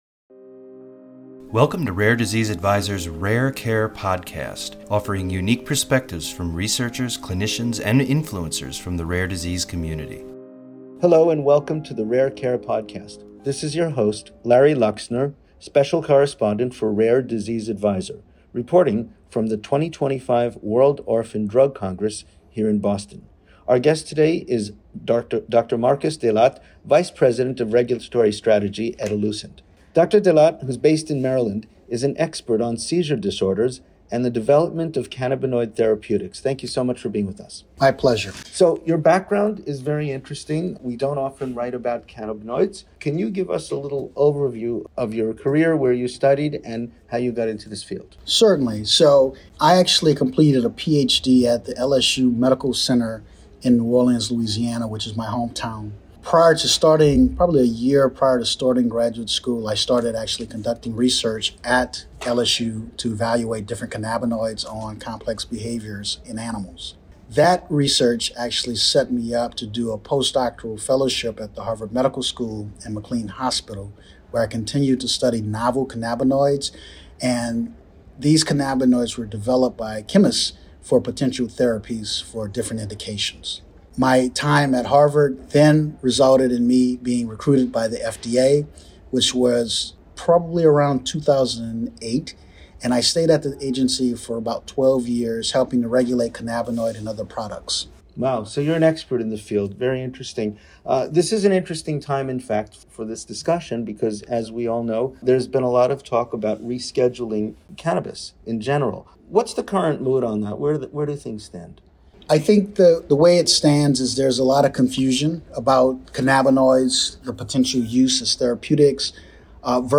Rare Care Podcast / An Interview